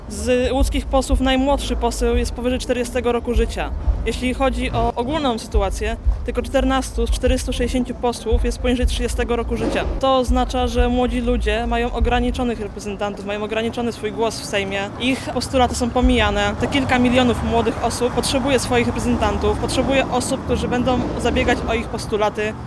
powiedziała na dzisiejszej konferencji